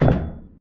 Step2.ogg